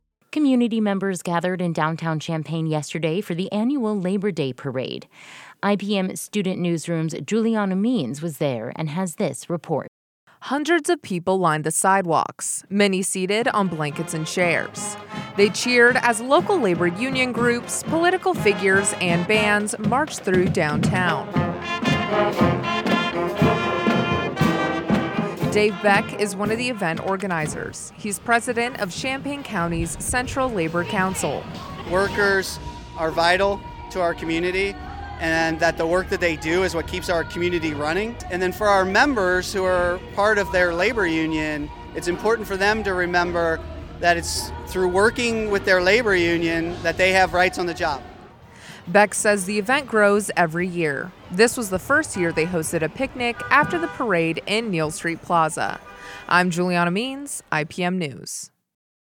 CHAMPAIGN Music, marchers and community pride filled downtown Champaign Monday as locals gathered for the Labor Day parade.